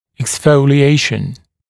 [eksˌfəulɪ’eɪʃn] [эксˌфоули’эйшн] выпадение молочных зубов